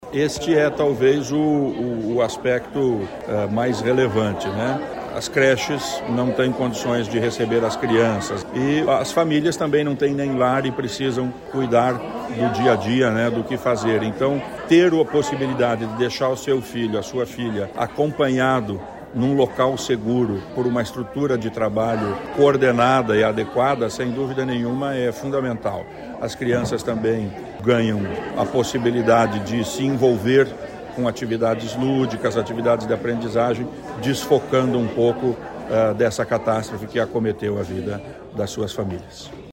Sonora do secretário da Ciência, Tecnologia e Ensino Superior, Aldo Bona, sobre o reparo de creche em Rio Bonito do Iguaçu com ajuda de servidores da Unioeste